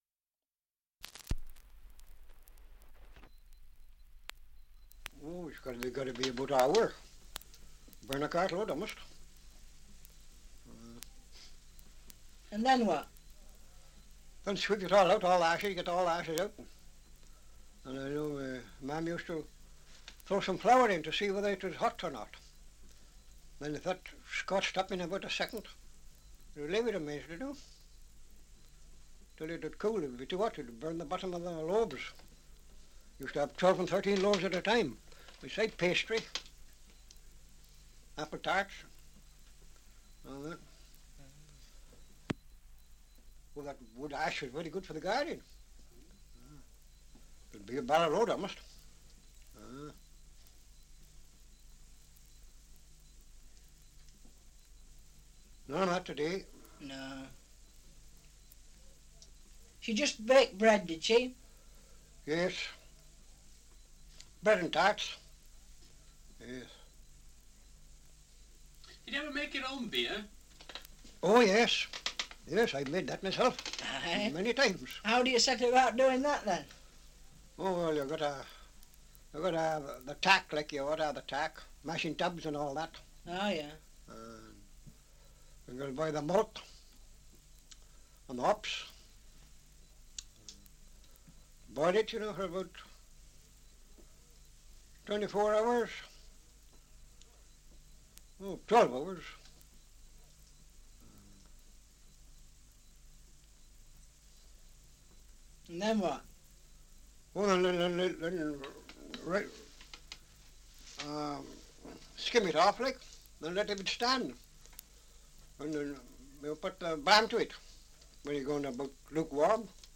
2 - Survey of English Dialects recording in Chirbury, Shropshire
78 r.p.m., cellulose nitrate on aluminium